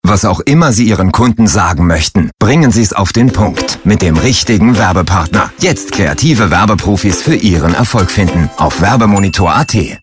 Die Expertentipps werden jeweils von niederösterreichischen Firmeninhabern gesprochen, um das kreative Potential für potentielle Auftragnehmer aufzuzeigen.